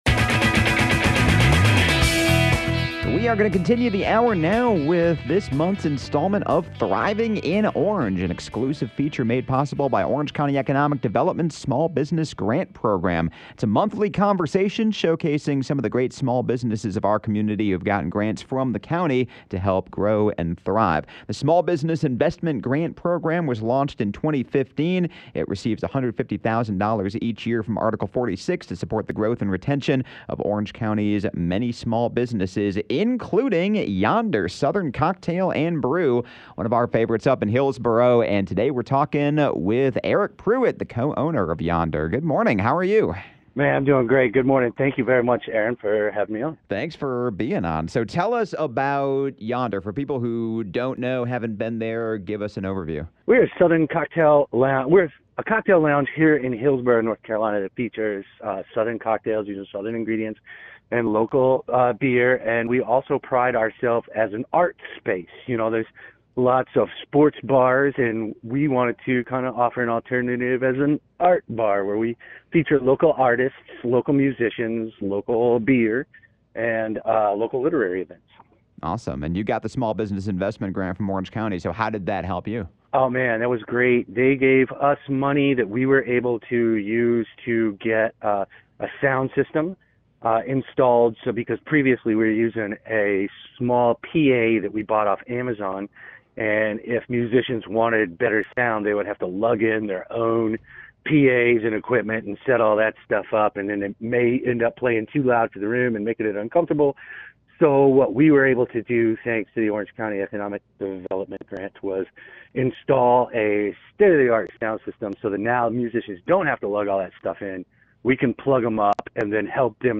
A monthly conversation discussing Orange County’s small business grant program, which began in 2015 and provides $150,000 in small business support each year to local businesses.